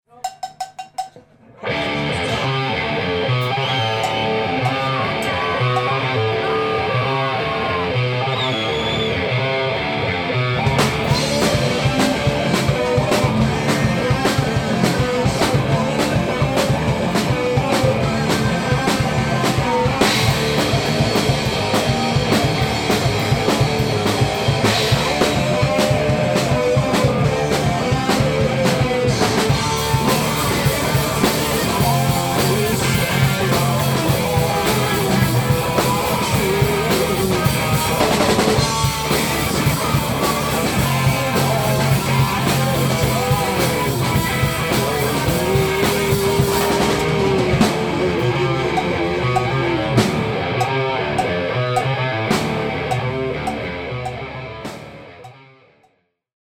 ZOOM H2 Digital Recorder – nagranie testowe 2-kanałowe